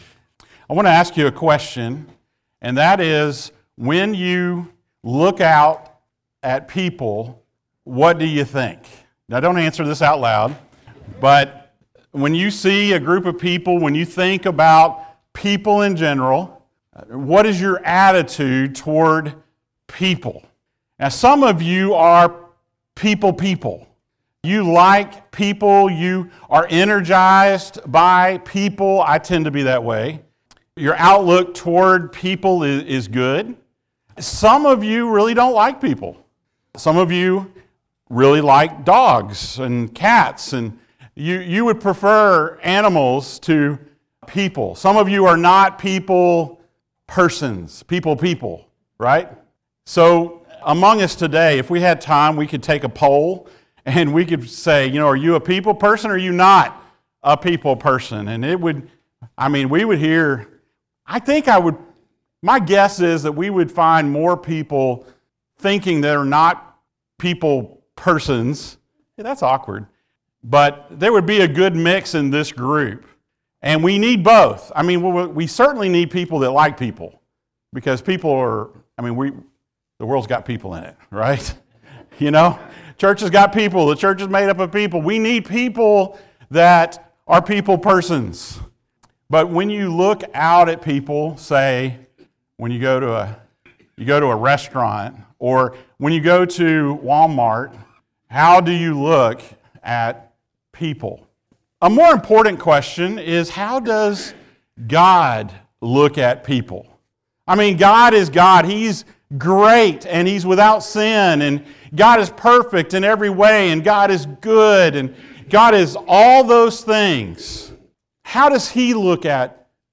The Life and Ministry of Jesus Passage: Matthew 9:35-38 Service Type: Sunday Morning Thank you for joining us.